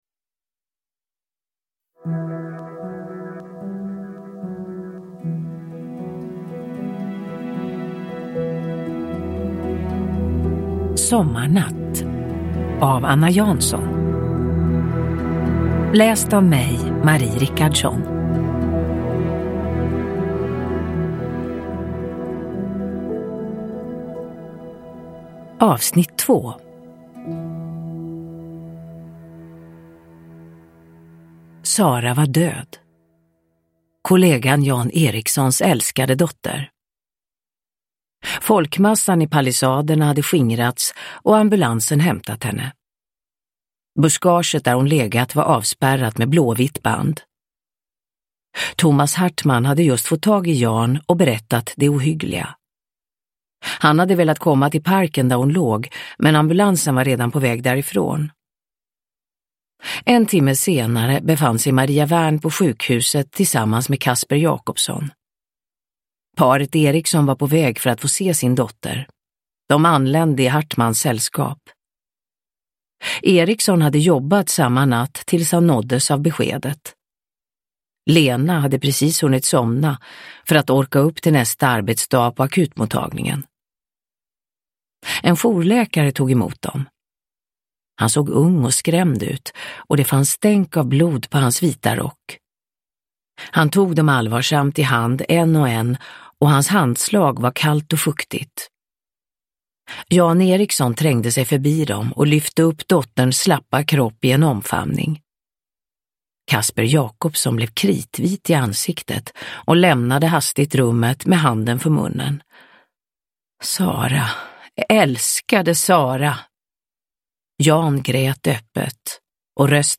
Sommarnatt - 2 – Ljudbok
Uppläsare: Marie Richardson